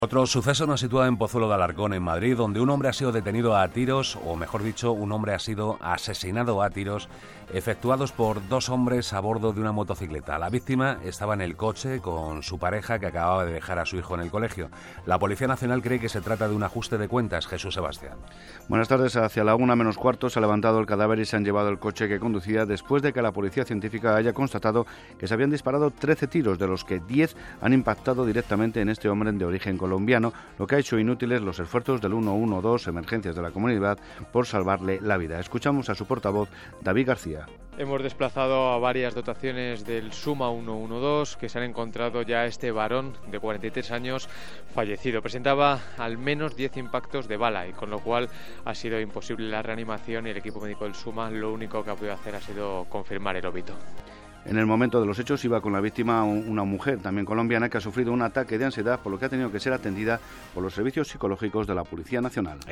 A continuación, te presentamos una noticia radiofónica emitida en Radio Nacional de España el 12 de marzo de 2018 para que te sirva de modelo a la hora de locutar tu producción:
Noticia.mp3